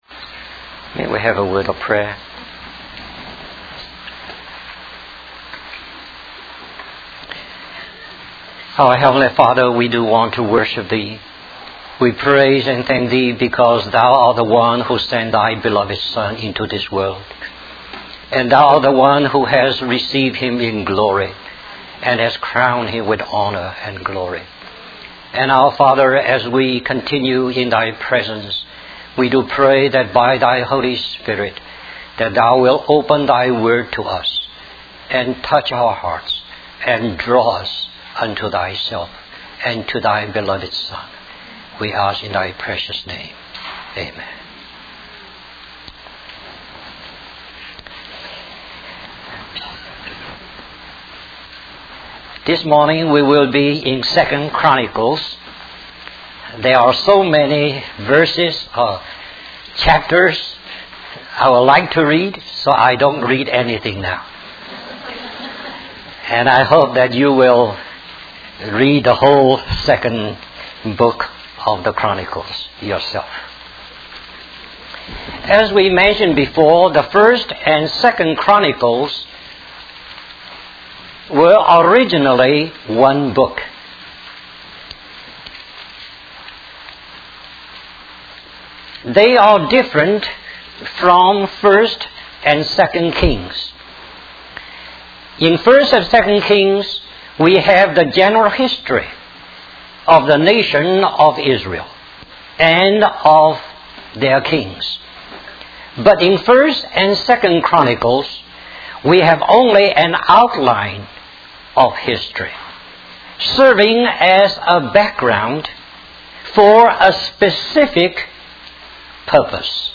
In this sermon, the speaker discusses the importance of having a pure and undivided heart towards God. He uses the examples of four kings from the Bible - Asa, Jehoshaphat, Hezekiah, and Josiah - to illustrate this point.